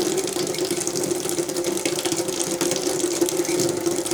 waterf.wav